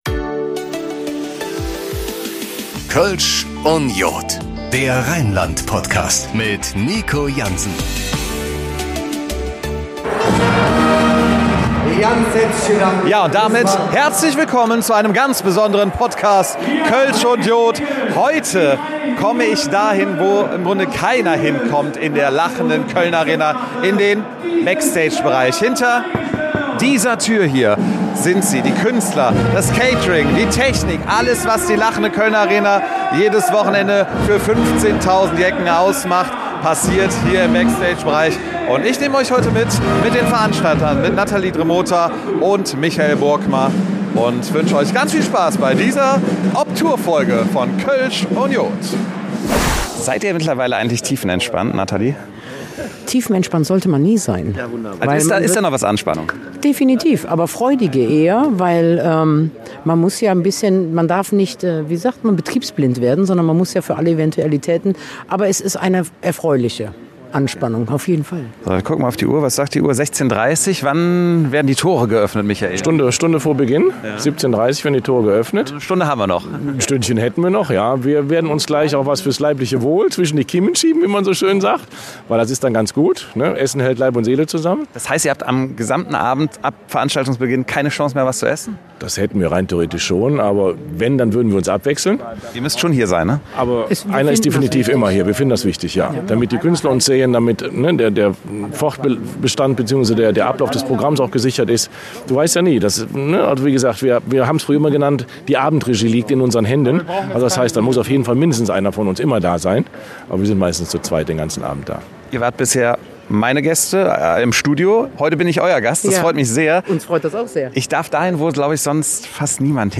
Er nimmt euch mit Backstage - hinter die Kulissen der Lachenden Kölnarena